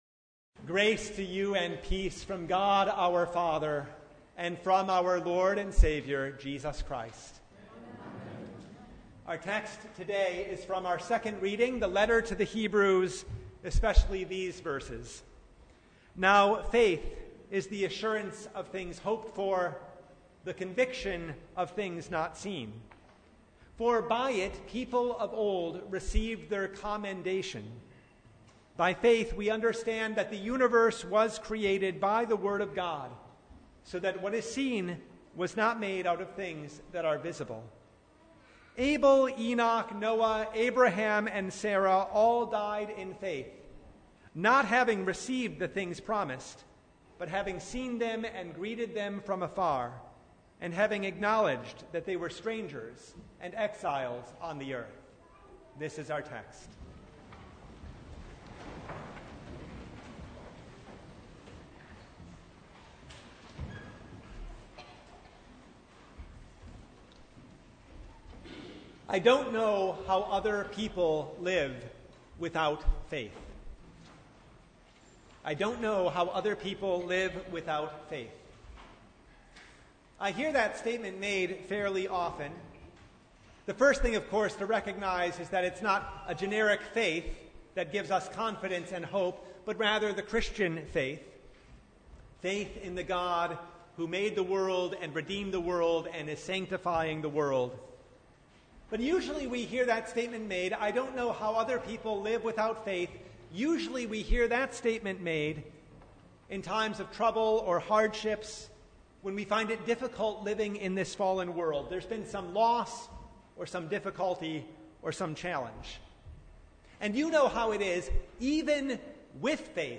Sermon from First Sunday in Martyrs’ Tide (2022)